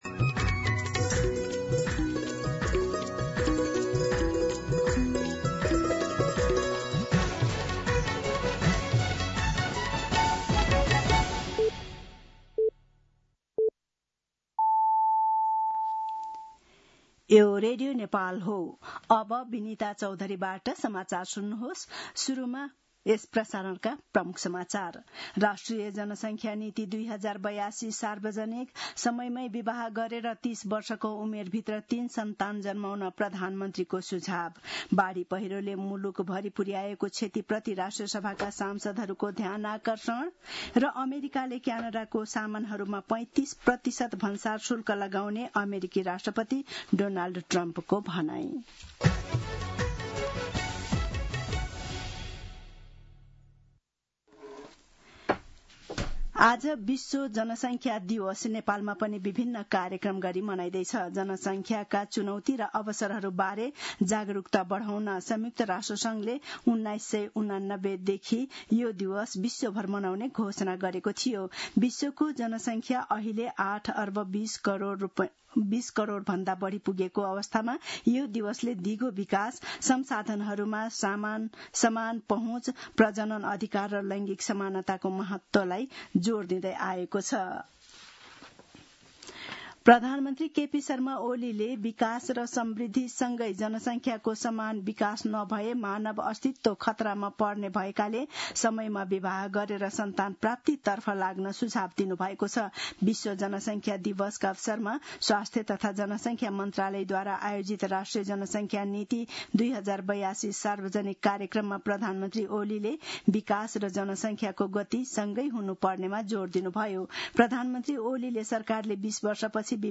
दिउँसो ३ बजेको नेपाली समाचार : २७ असार , २०८२
3-pm-Nepali-News.mp3